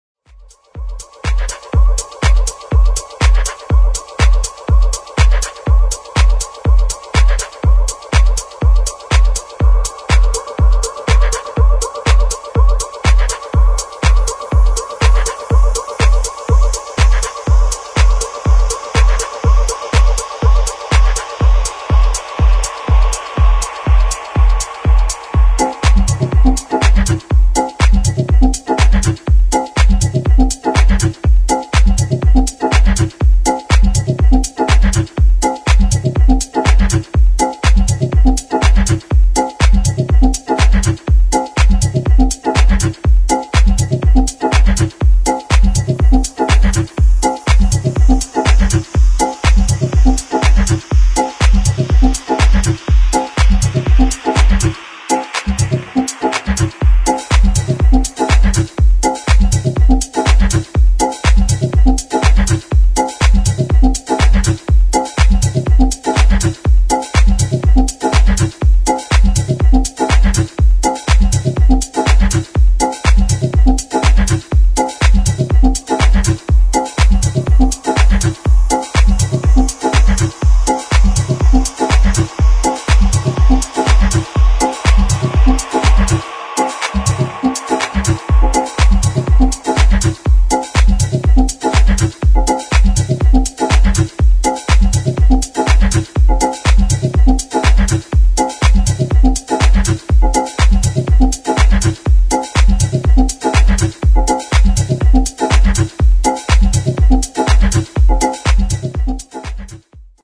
[ TECH HOUSE ]